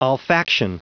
Prononciation du mot olfaction en anglais (fichier audio)
Prononciation du mot : olfaction